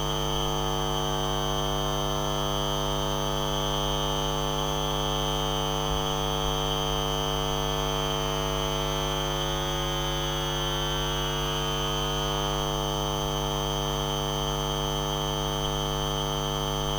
In this example an electric train passes by, about 100 meters outside my home.
There is initially a surge in power supply into the home and then a dip by listening to the sound of the phenomenon.